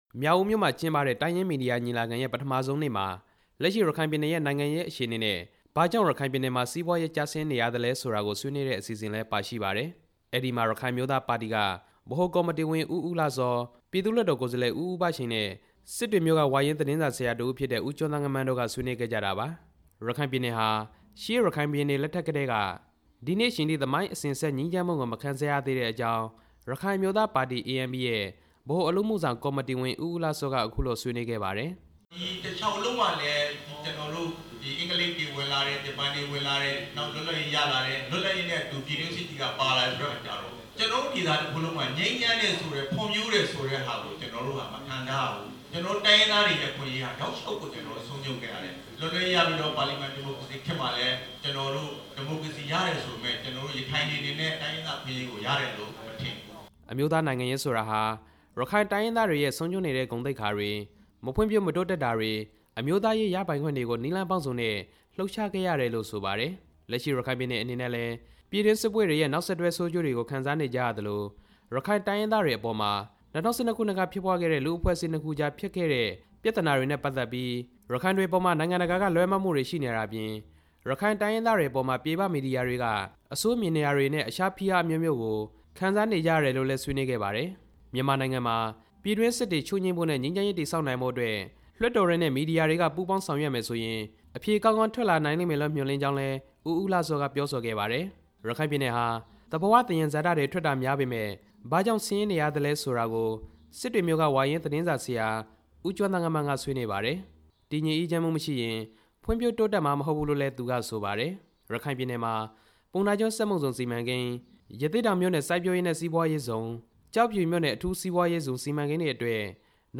မြောက်ဦးမြို့ကနေ သတင်းပေးပို့ထား ပါတယ်။